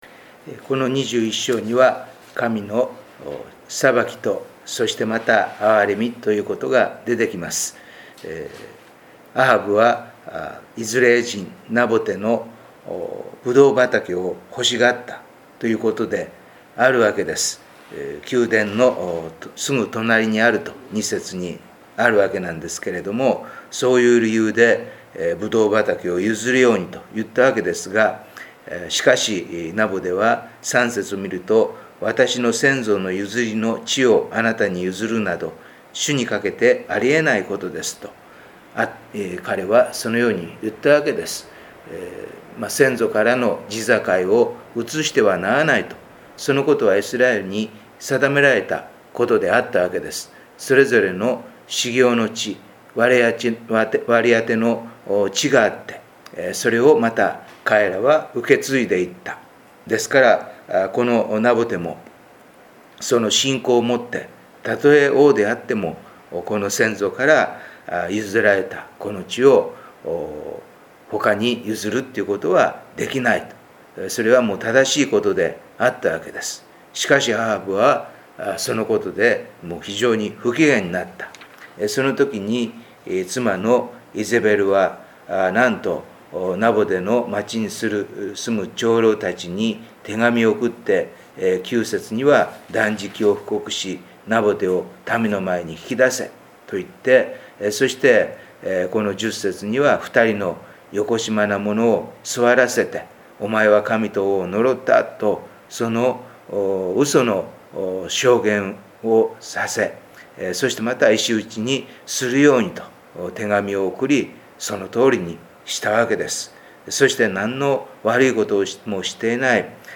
8月のデボーションメッセージ